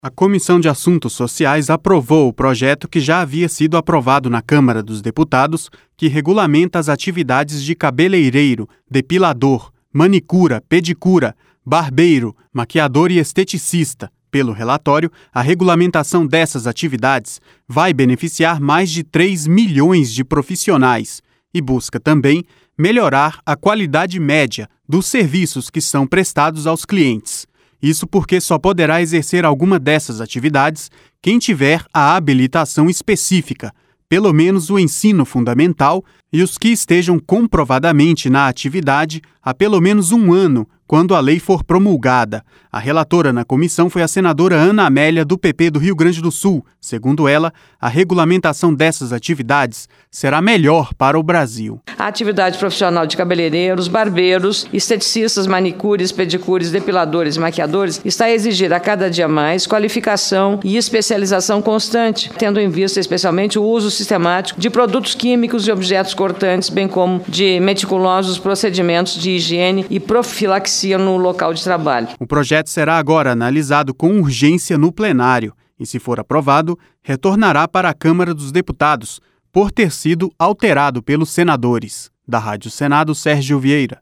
A relatora na Comissão foi a senadora Ana Amélia, do PP do Rio Grande do Sul.